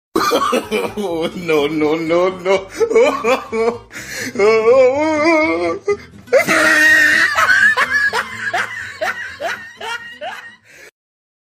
Risa No No No Jajaja Bouton sonore